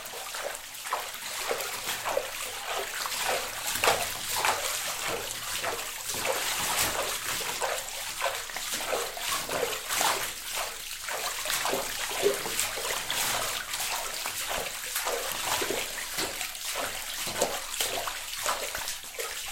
描述：Midway between a buzz and a squelch, this was caused by dismissing a notification on a tablet. Recorded with an induction coil microphone.
标签： fieldrecording squelch buzz inductioncoil tablet scifi
声道立体声